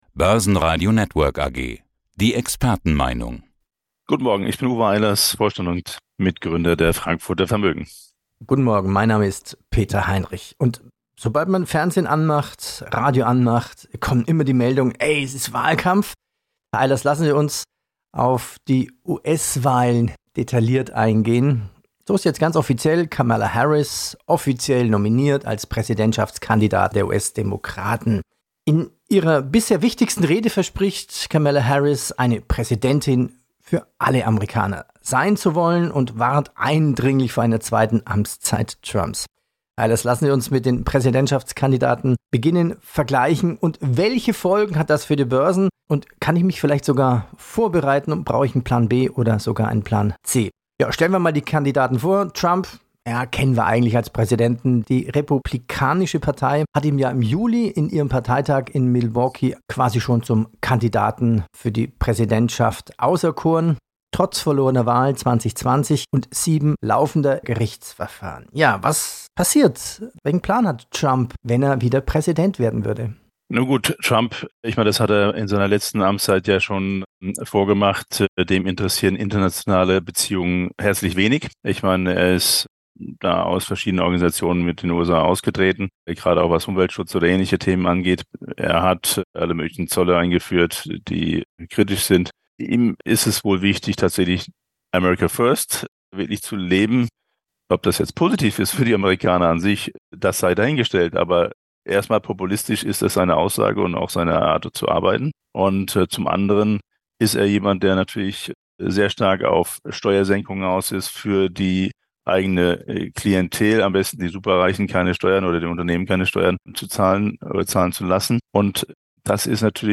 Radiobeitrag: Wenn Trump die US-Wahl gewinnt, sehe ich schwarz.